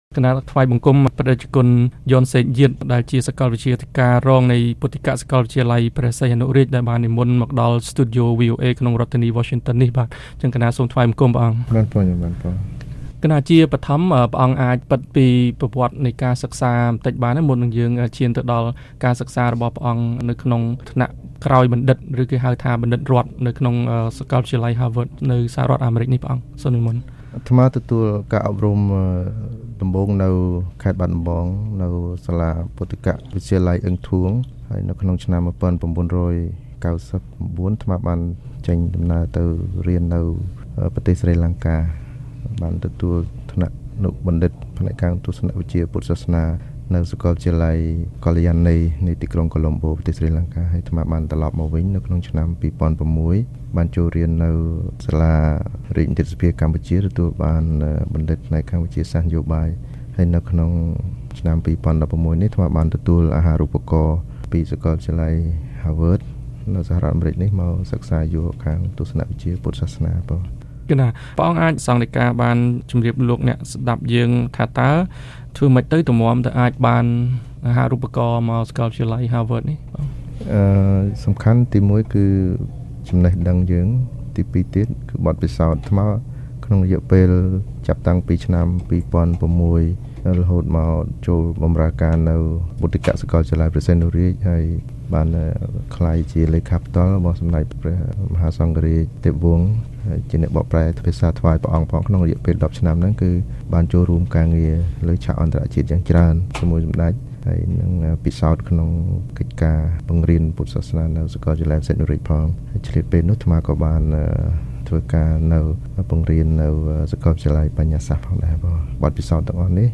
បទសម្ភាសន៍ VOA៖ ព្រះសង្ឃអាចជួយសង្គមជាតិបាន លុះត្រាតែព្រះសង្ឃជាអ្នកចេះដឹងខ្ពង់ខ្ពស់